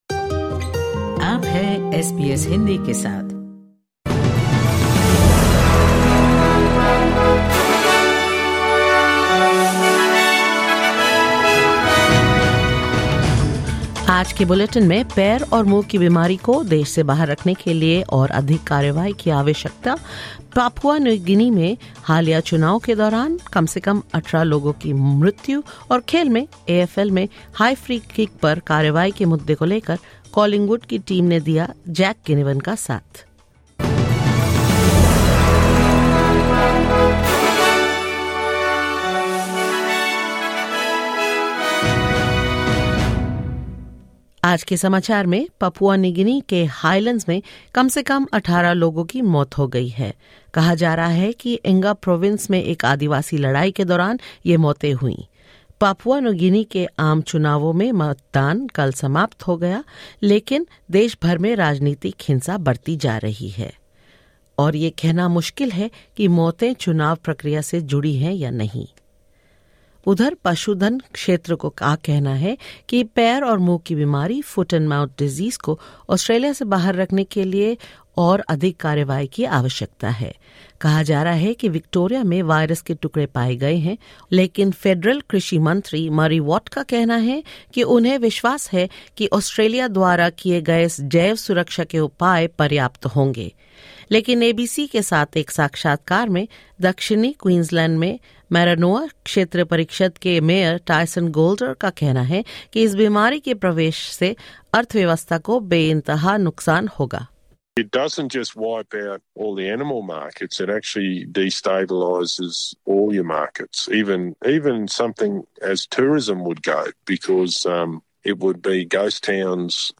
In this latest SBS Hindi bulletin: At least 18 people killed in Papua New Guinea during a tribal fight in Enga province; Australia's Livestock sector calls for more urgent action to keep foot-and-mouth disease out of the county; In sports, Collingwood teammates rally around Jack Ginnivan amid the free kicks for high contacts debate and more.